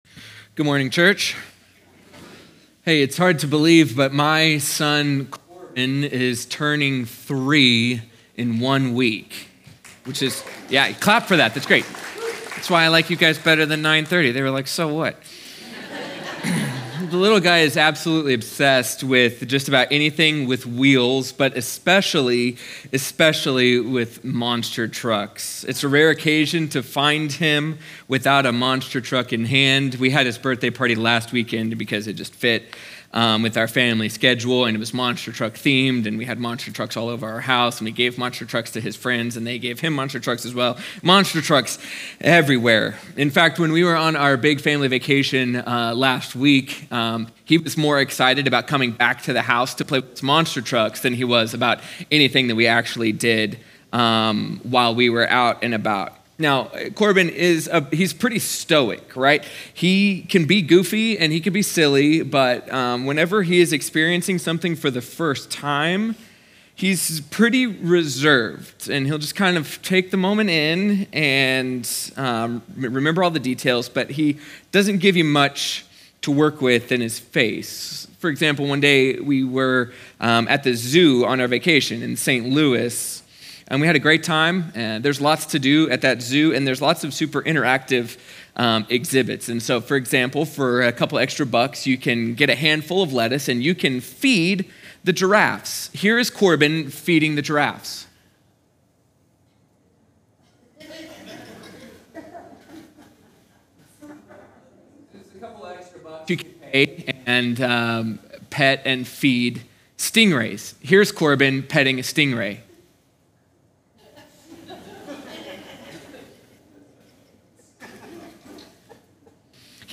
sermon audio 0525.mp3